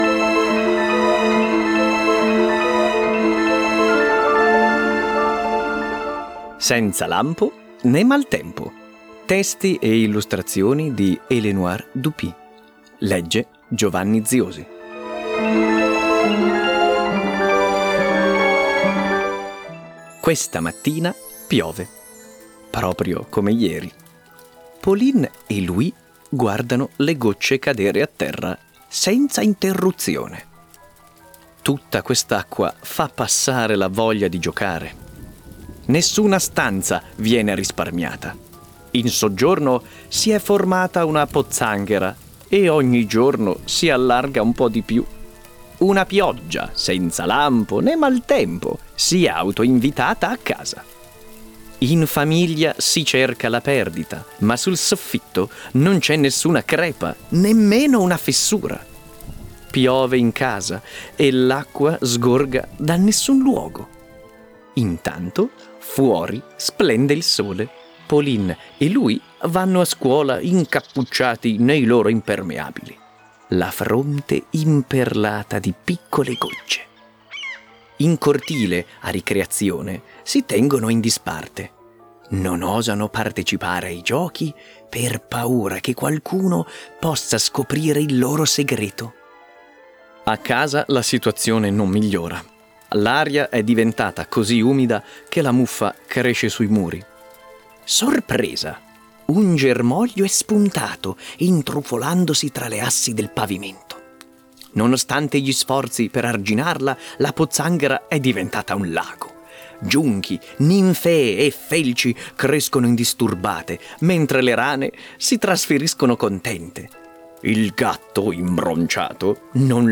- Senza lampo nè maltempo - con tappeto sonoro